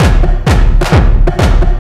ELECTRO 02-R.wav